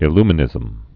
(ĭ-lmə-nĭzəm)